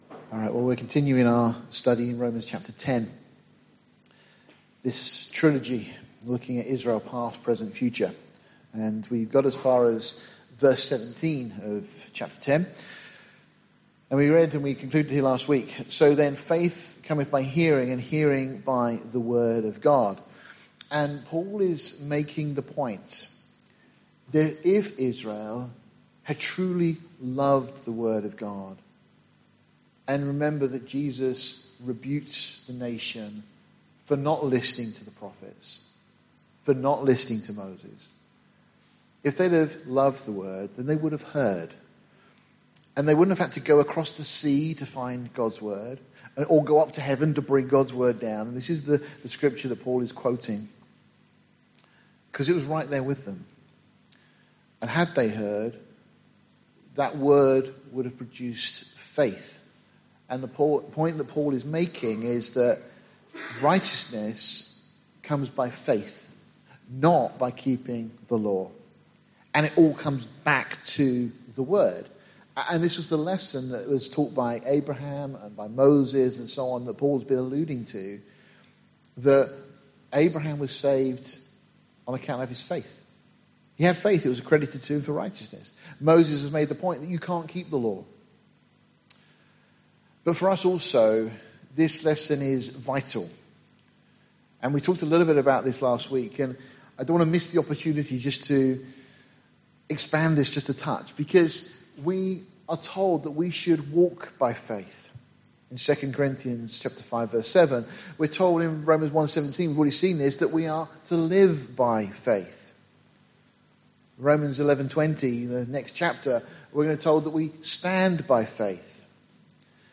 Series: Sunday morning studies Tagged with Faith , Israel , verse by verse